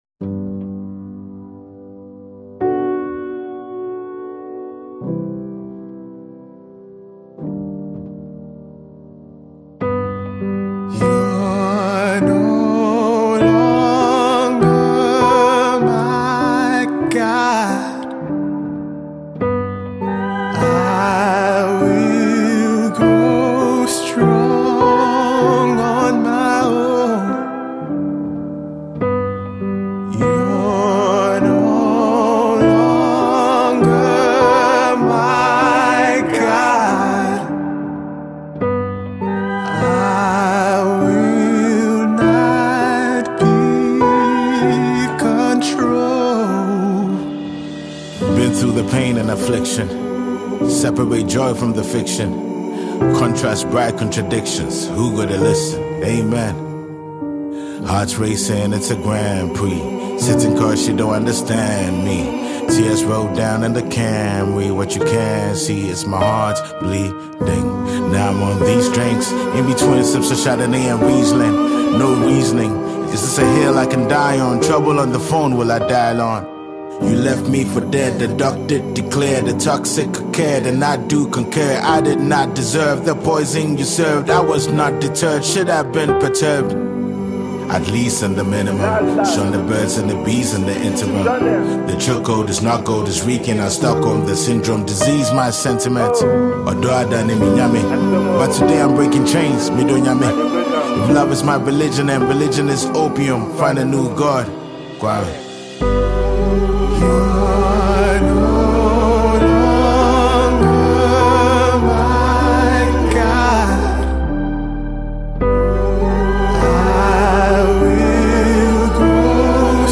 Highly-rated Ghanaian rapper